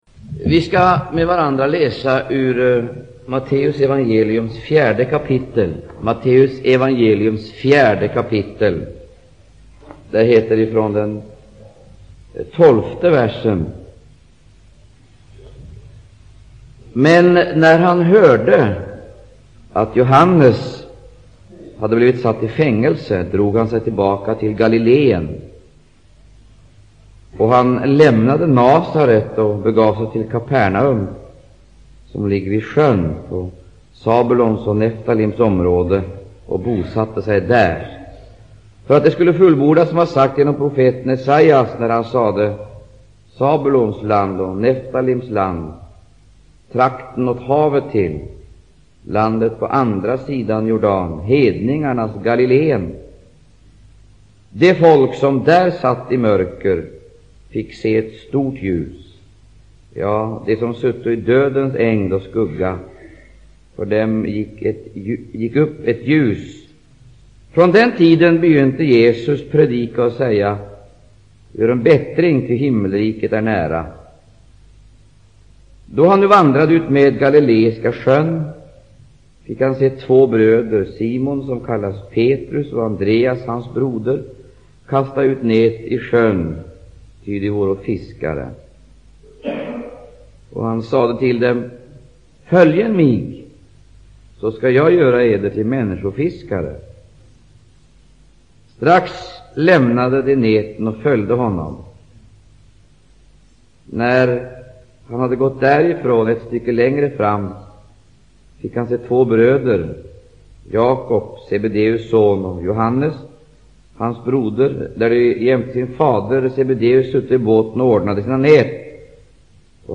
Undervisning